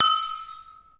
Notification Signal
game-design gui information location notification position sound effect free sound royalty free Sound Effects